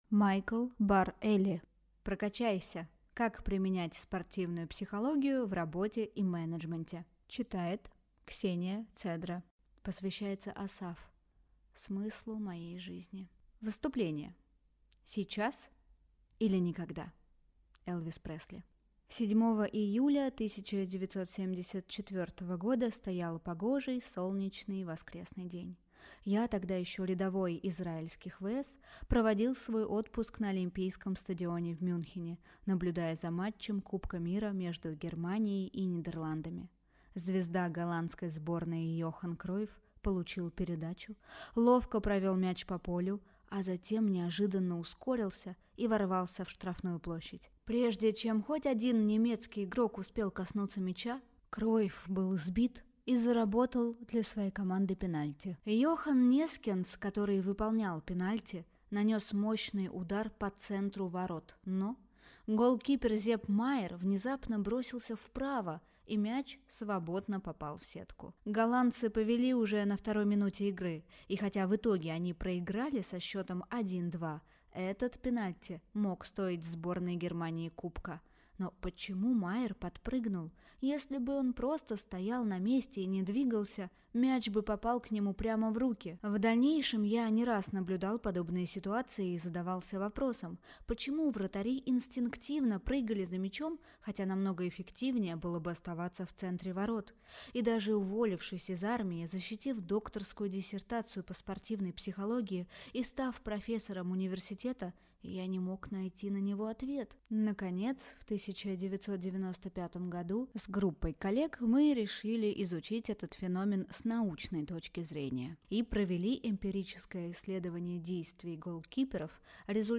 Аудиокнига Прокачайся! Как применять спортивную психологию в работе и менеджменте | Библиотека аудиокниг